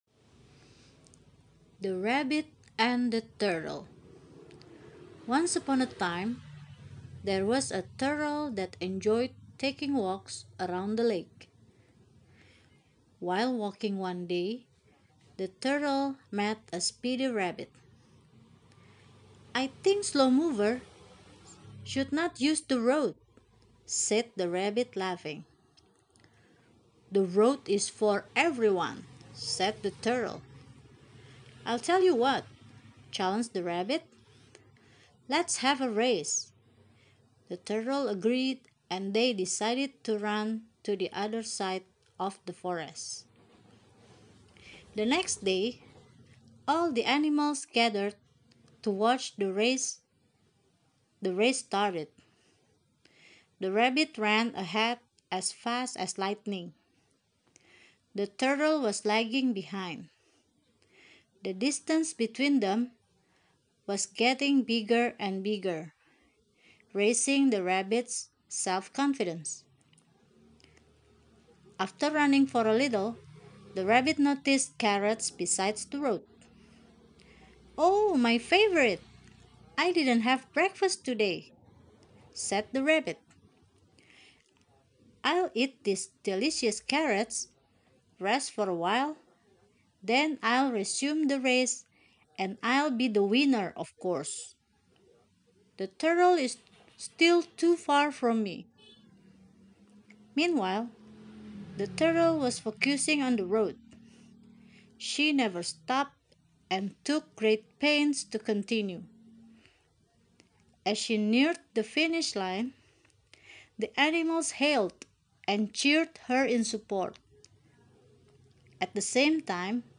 Dongeng ini juga dilengkapi dengan audio.
2. Bacalah dengan benar sesuai dengan yang ada pada audio dan dongengnya, ikuti pelafalan dan nadanya
Audio-Rabbit-and-Turtle.mp3